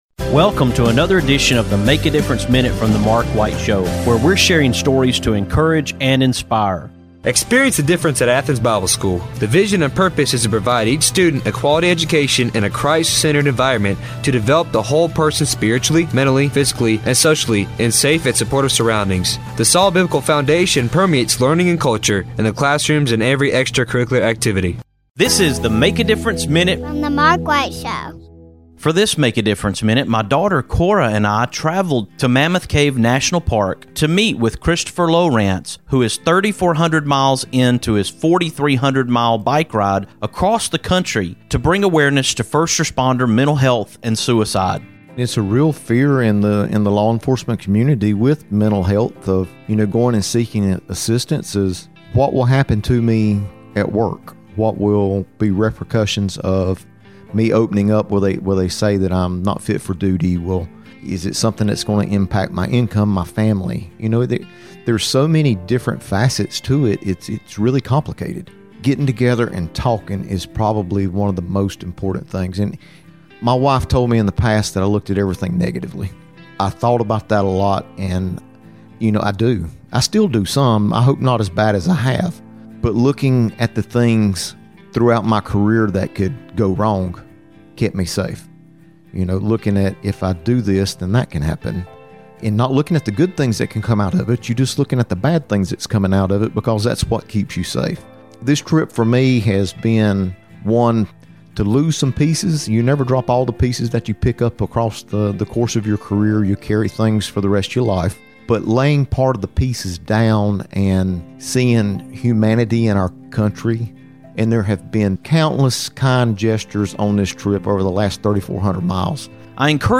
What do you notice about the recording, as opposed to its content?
On this MADM, I am sharing part of our conversation.